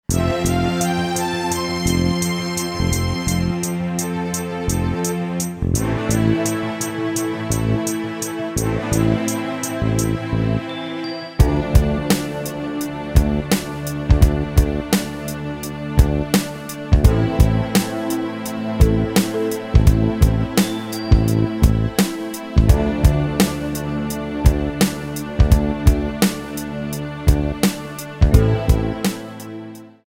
klick & play MP3/Audio demo